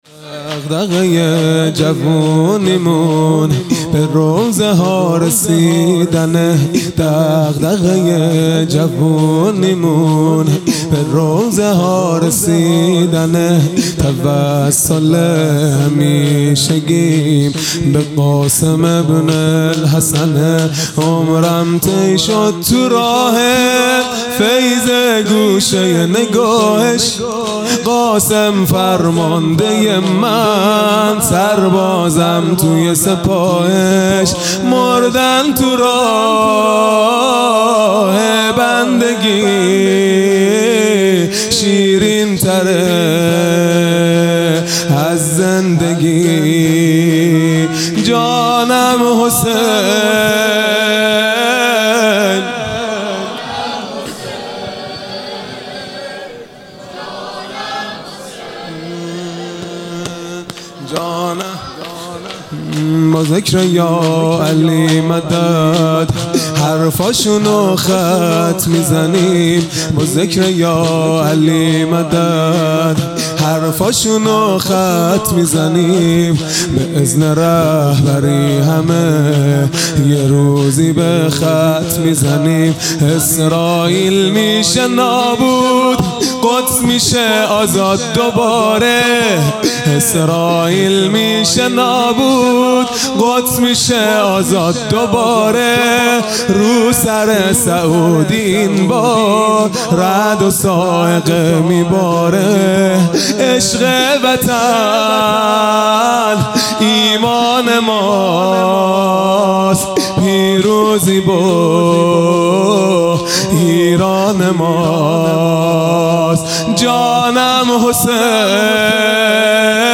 خیمه گاه - هیئت بچه های فاطمه (س) - شور | دغدغۀ جوونیمون
محرم ۱۴۴۱ | شب هشتم